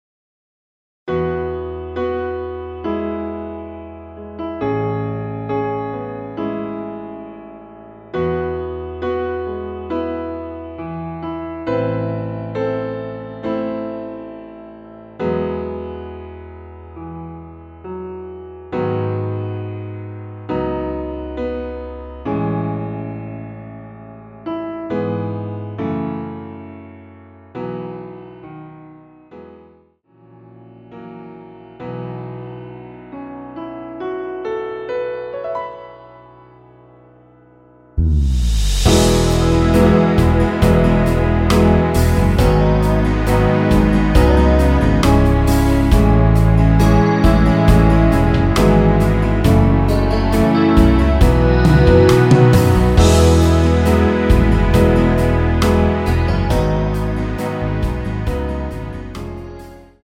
라이브용 버전2절 없이 진행 됩니다.(아래 동영상및 가사 참조)
◈ 곡명 옆 (-1)은 반음 내림, (+1)은 반음 올림 입니다.
앞부분30초, 뒷부분30초씩 편집해서 올려 드리고 있습니다.
중간에 음이 끈어지고 다시 나오는 이유는